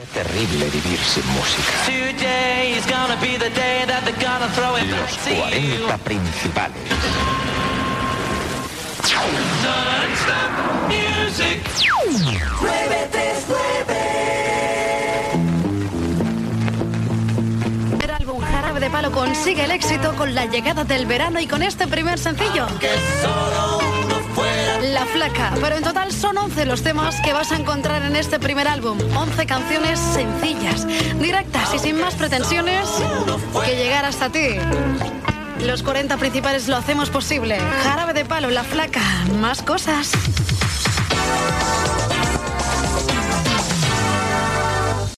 Musical
FM
Gravació realitzada a València.